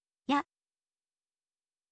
ออกเสียง: ya, ยะ